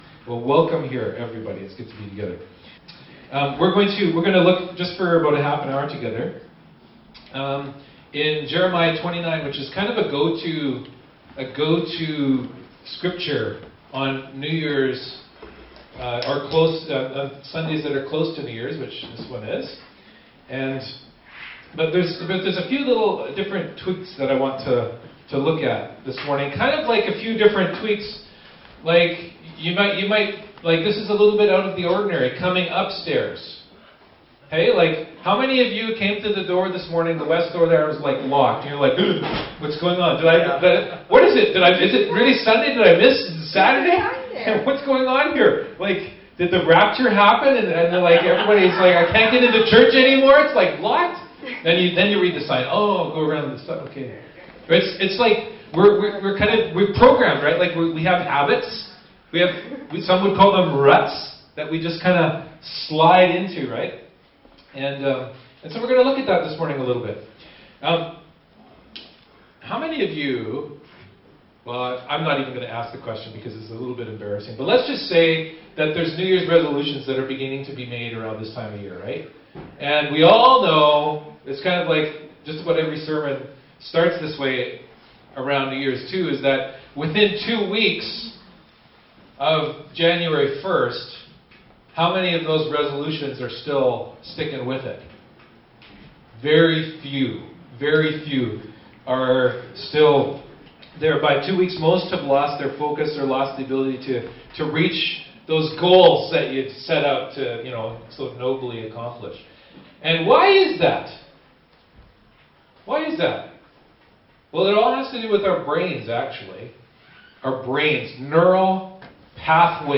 Service Type: Upstairs Gathering